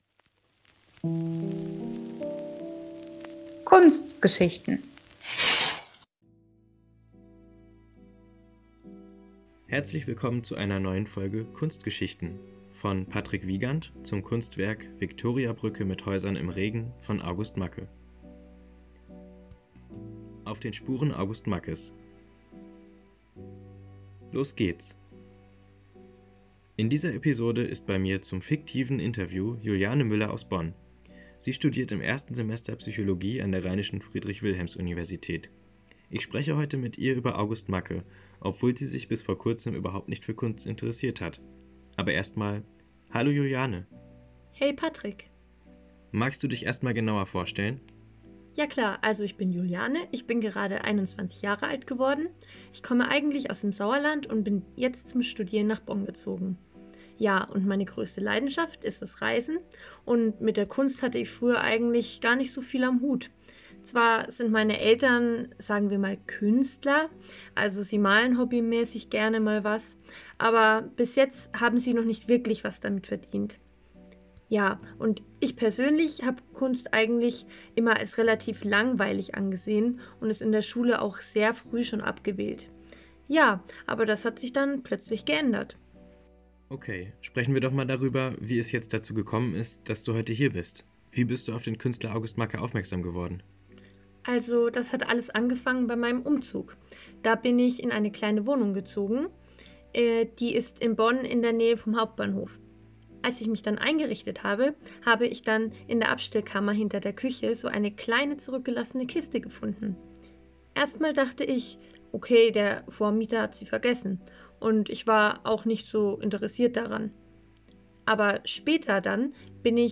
Das fiktive Interview nimmt Bezug auf Mackes reale Biographie.